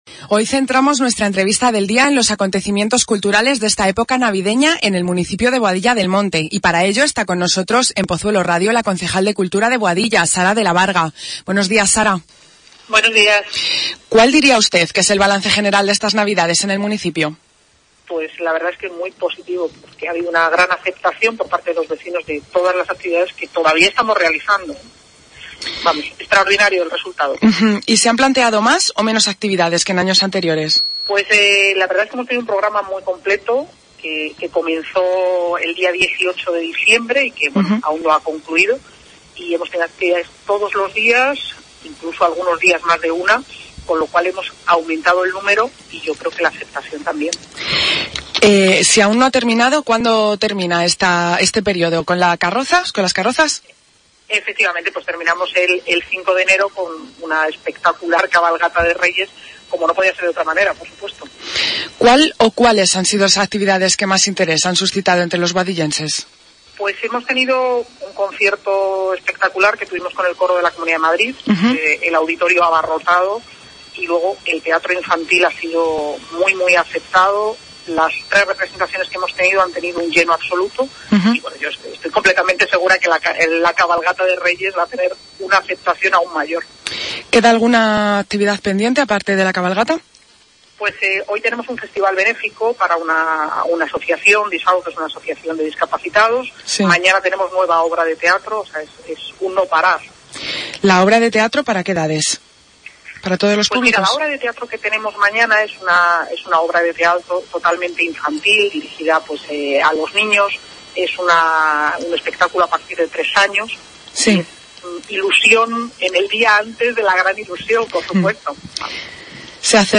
La concejal de Cultura en Boadilla, Sara de la Varga, ha hablado para Pozuelo Radio en su Informativo Regional sobre los acontecimientos culturales celebrados y previstos para esta época navideña en el municipio.